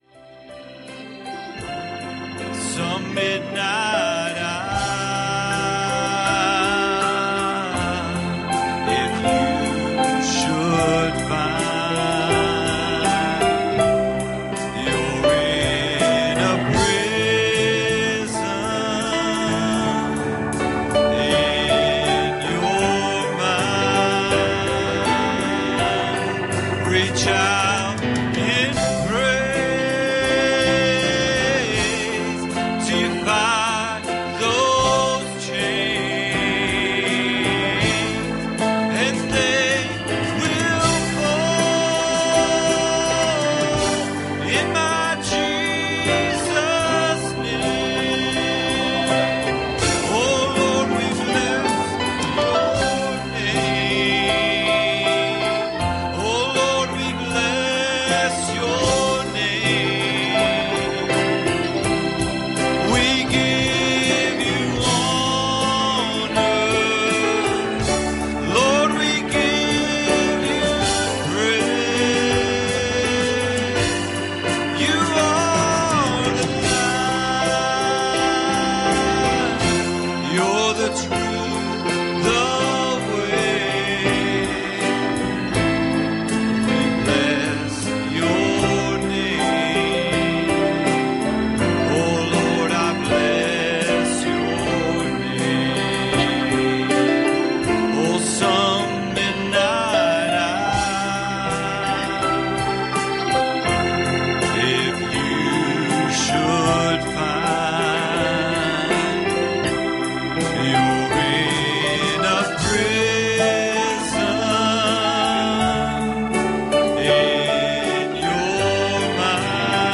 Passage: 1 Peter 1:23 Service Type: Sunday Morning